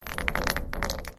grenade_roles_in1.wav